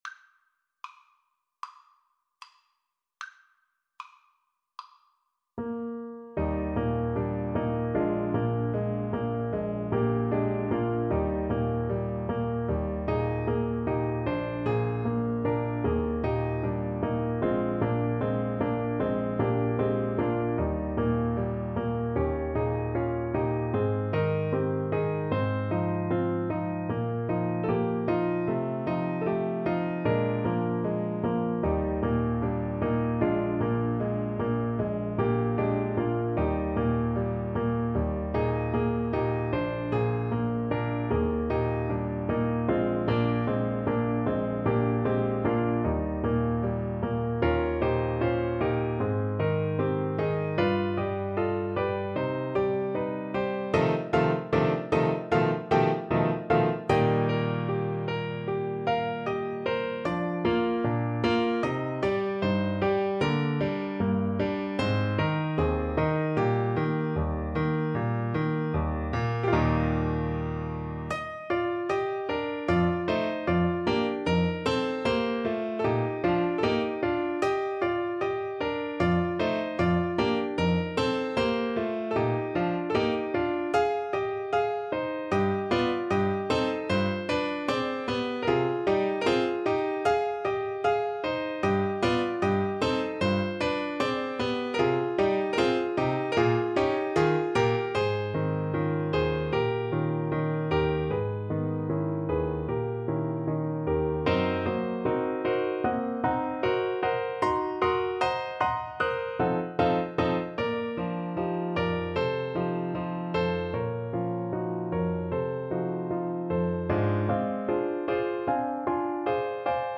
Eb major (Sounding Pitch) (View more Eb major Music for Trombone )
Lento ma non troppo = c.76
Classical (View more Classical Trombone Music)